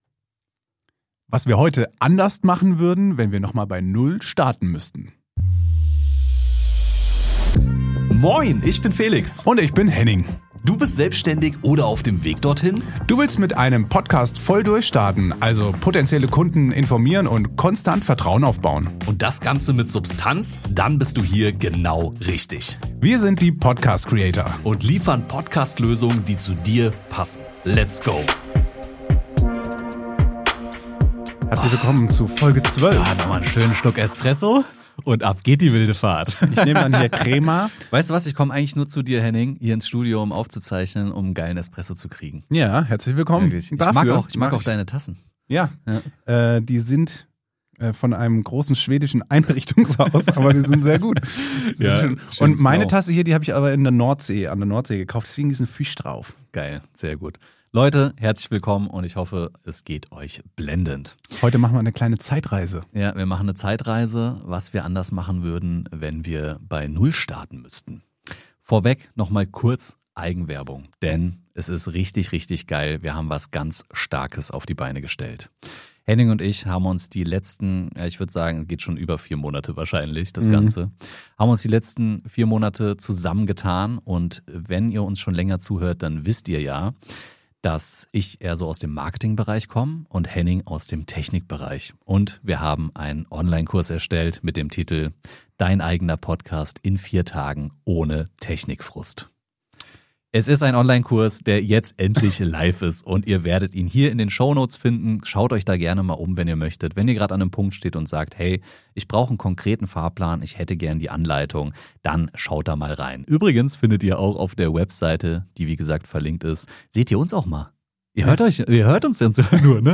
Aufgenommen in der Medienschmiede im Rhein-Main-Gebiet (Taunus, Nähe Frankfurt).